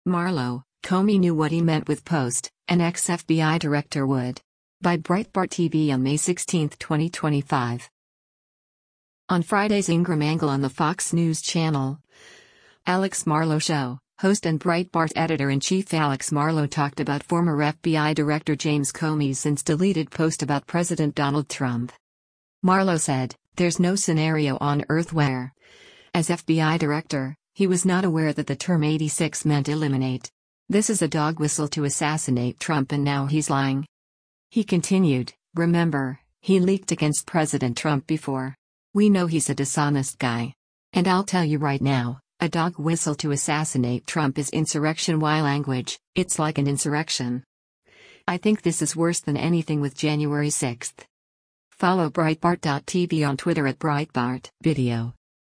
On Friday’s “Ingraham Angle” on the Fox News Channel, “Alex Marlow Show,” host and Breitbart Editor-in-Chief Alex Marlow talked about former FBI Director James Comey’s since-deleted post about President Donald Trump.